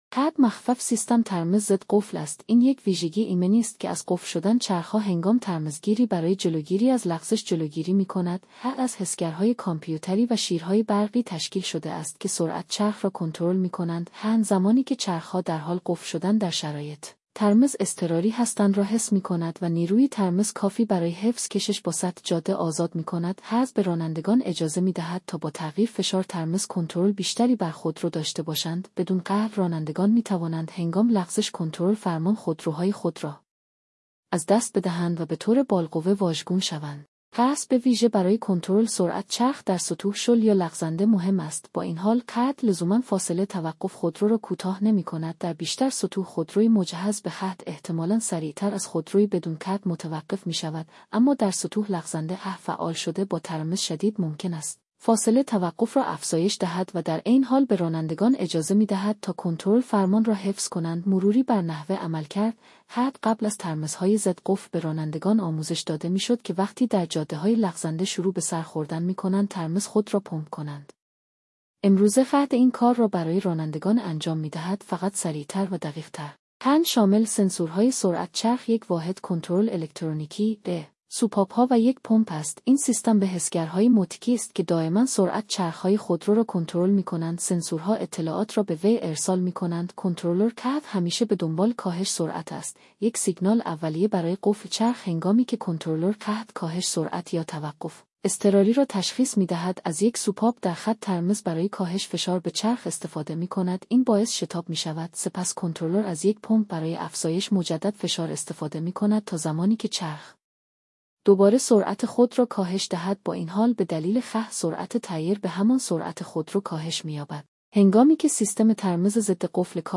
abs چیست با صدای هوش مصنوعی: